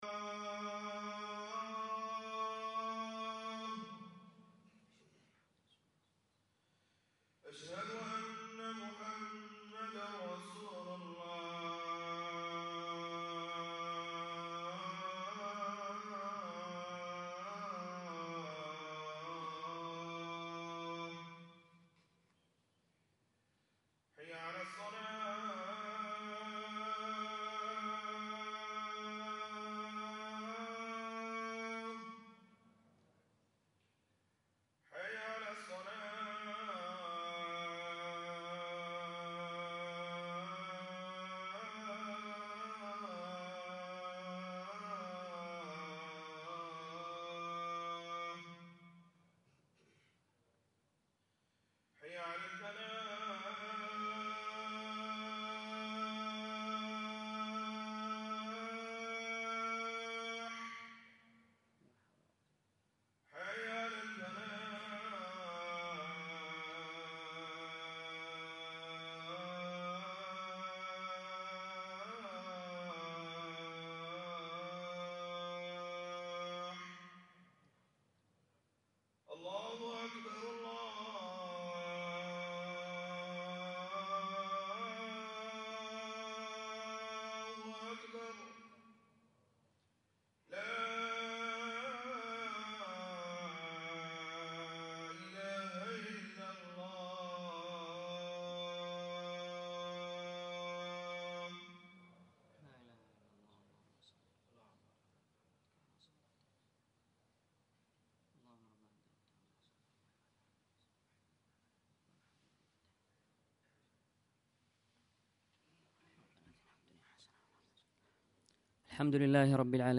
سؤال وجواب
الخطبه